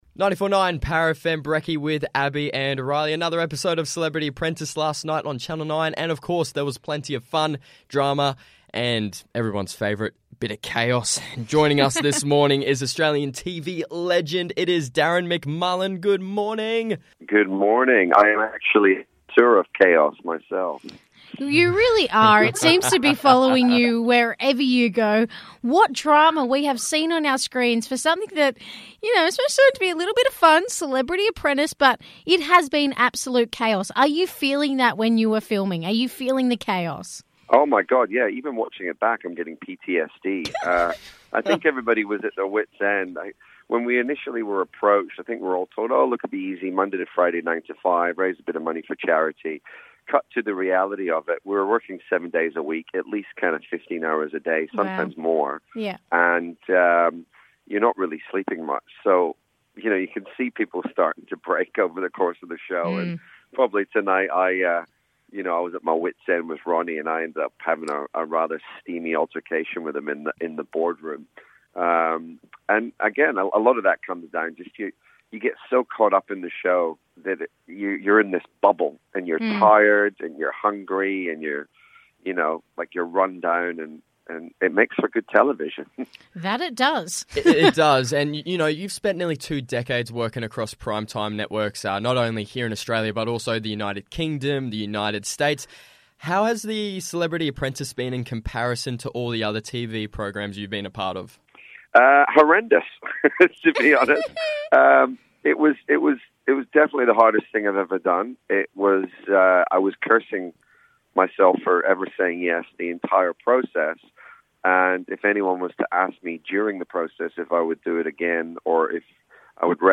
TV personality, Darren McMullen, joined the breakfast show to chat about his journey on Celebrity Apprentice on Channel 9.